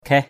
/kʱɛh/ 1.